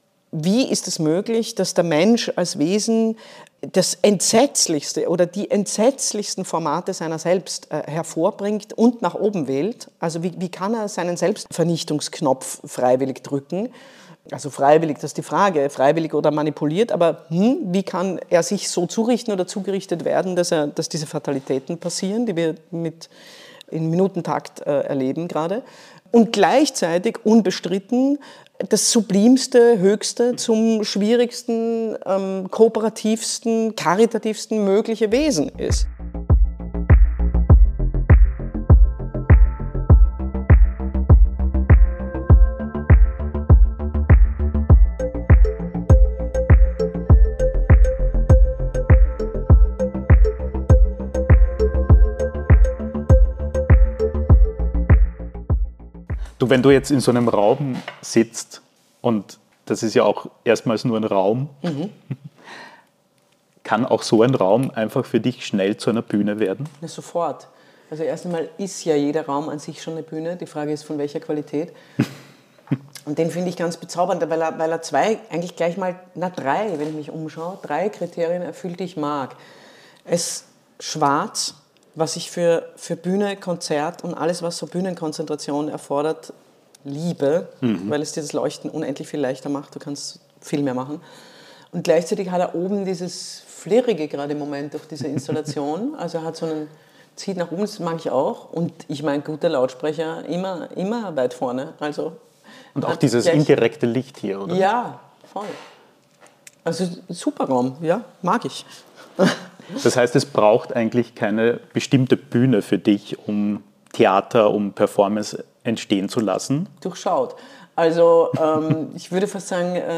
Ein Gespräch über Theater als vielschichtiges Kunstverständnis und über die ungeheure Doppelgestalt des Menschen.